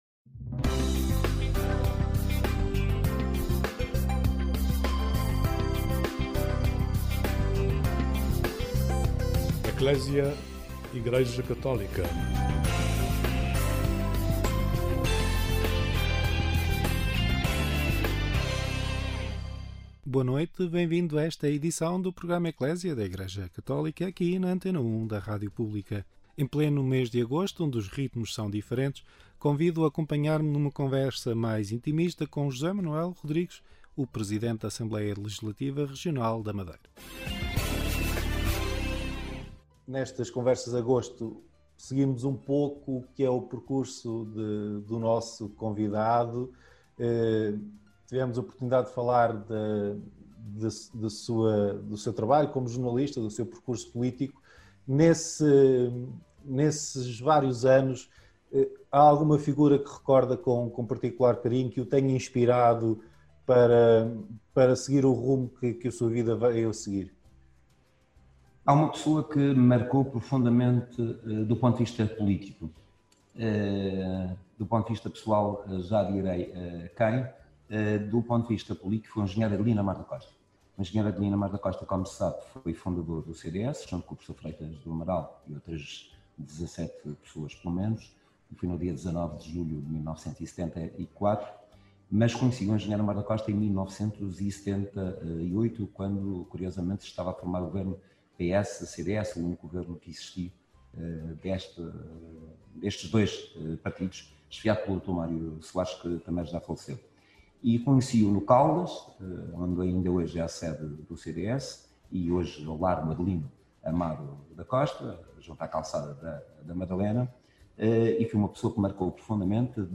O presidente da Assembleia Legislativa Regional da Madeira (ALRM) fala dos testemunhos de vida notáveis que encontra nas suas visitas às IPSS. José Manuel Rodrigues recorda ainda a serenidade de São João Paulo II e o carisma de Adelino Amaro da Costa, figuras que o marcaram.